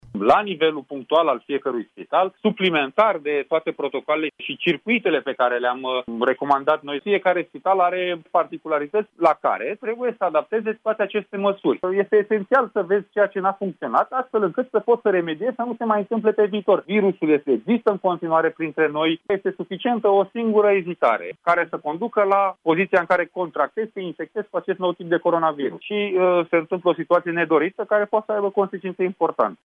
Într-un interviu, pentru Europa FM, secretarul de stat în Ministerul Sănătății Andrei Baciu a explicat cum au apărut aceste noi focare.
25mai-07-Andrei-Baciu-despre-focare-spitale-SCURTAT.mp3